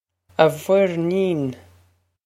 A vwer-neen
This is an approximate phonetic pronunciation of the phrase.